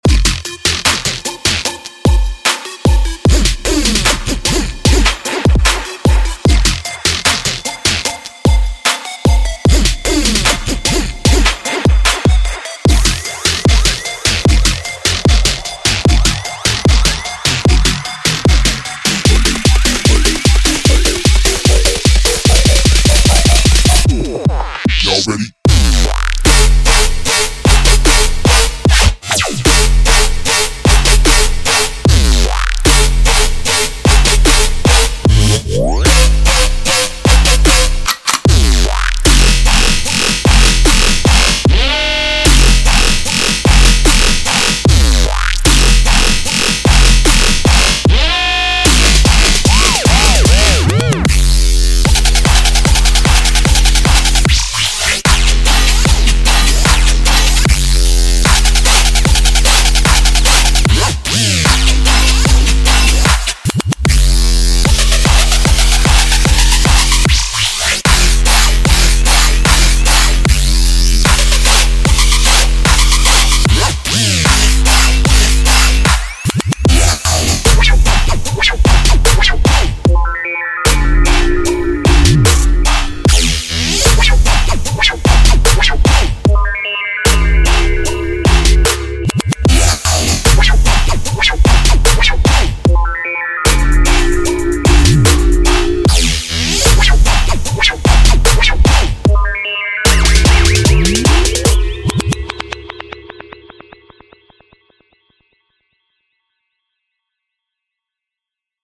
带有112种血清预设的音色，例如断断续续的低音序列，粉碎的低音咆哮，令人讨厌的低音刺，震撼的FX和充满活力的合成器，您将有一个起点将新歌曲带入新的高度！
72低音血清预设
24 Synth血清预设